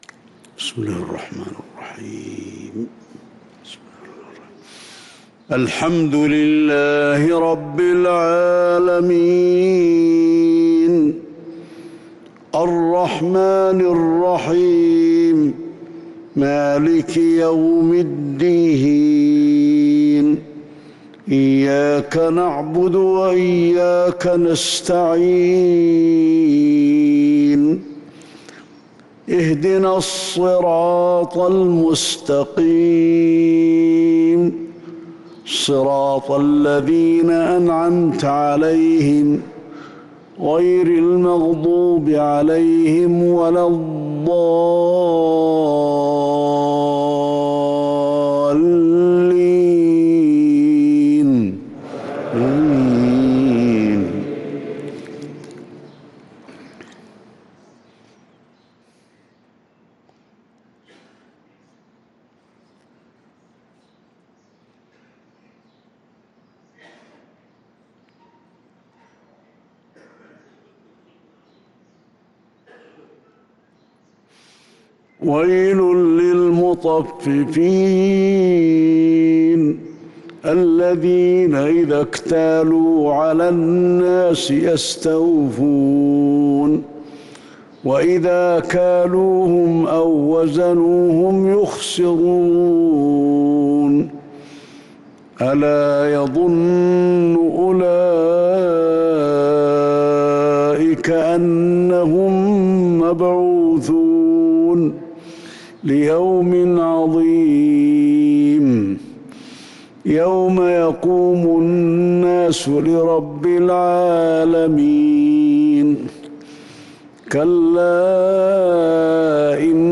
صلاة الفجر للقارئ علي الحذيفي 14 ذو الحجة 1443 هـ
تِلَاوَات الْحَرَمَيْن .